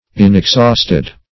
Search Result for " inexhausted" : The Collaborative International Dictionary of English v.0.48: Inexhausted \In`ex*haust"ed\, a. [Pref. in- not + exhausted: cf. F. inexhaustus.]